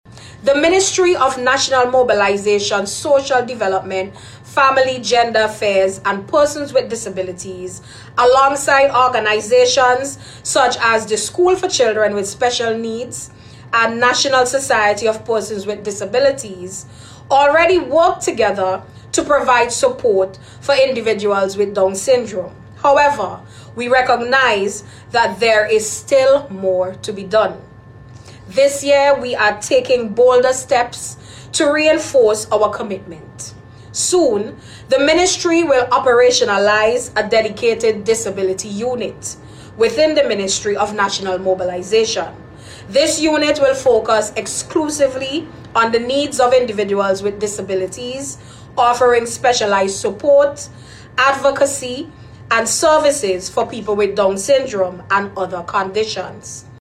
This was revealed by Minister of National Mobilization, Keisal Peters, during an address to commemorate World Down syndrome Day.